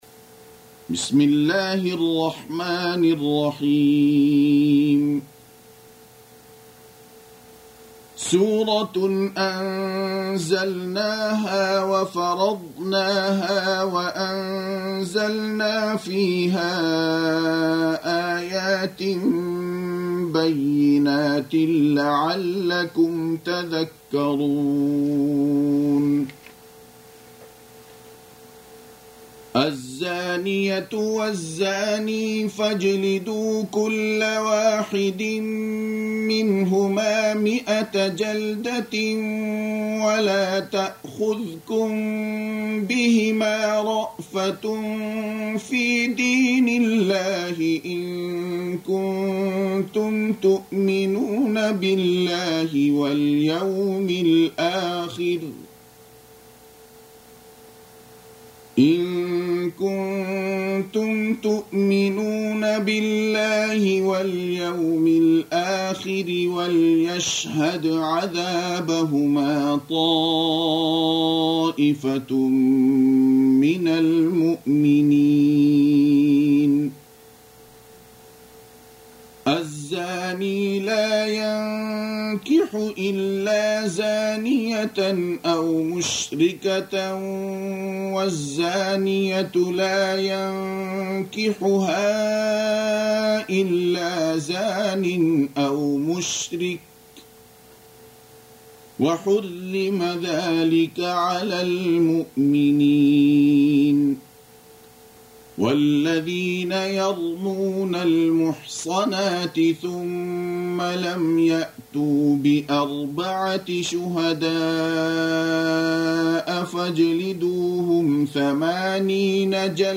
Audio Quran Tarteel Recitation
Surah Repeating تكرار السورة Download Surah حمّل السورة Reciting Murattalah Audio for 24. Surah An-N�r سورة النّور N.B *Surah Includes Al-Basmalah Reciters Sequents تتابع التلاوات Reciters Repeats تكرار التلاوات